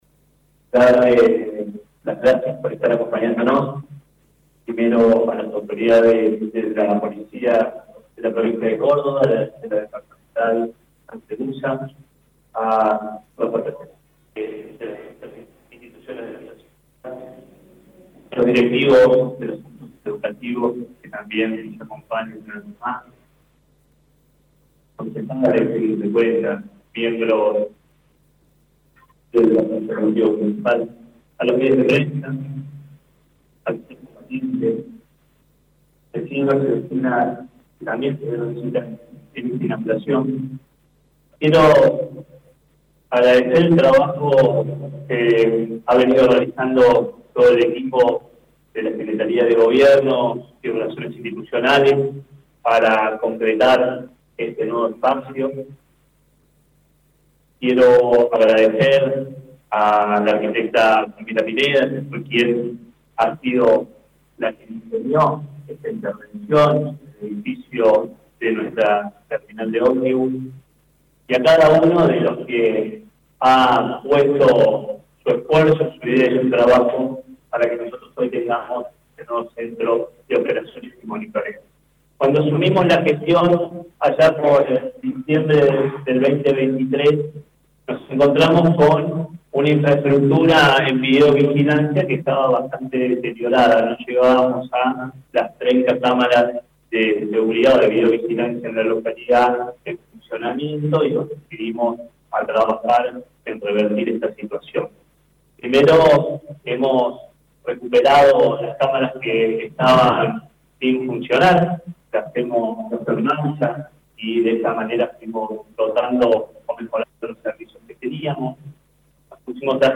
demarCHI-INAUG-29-08.mp3